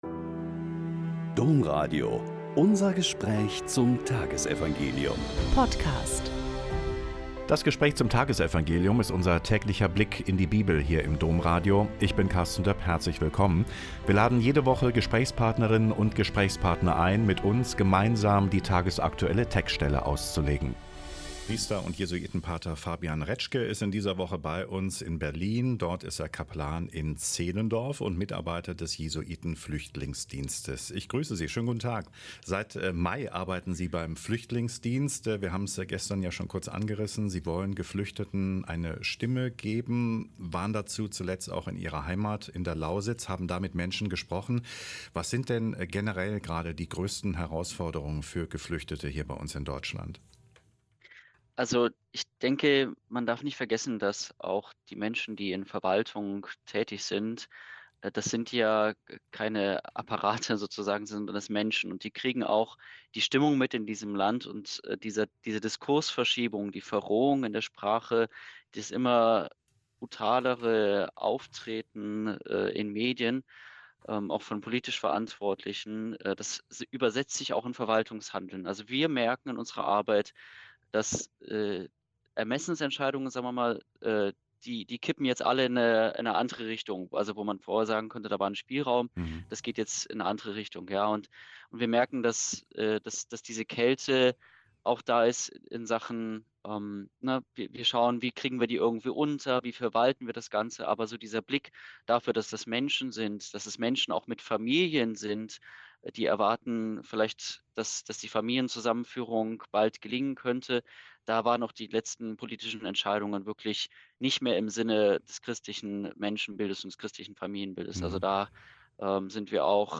Lk 9,57-62 - Gespräch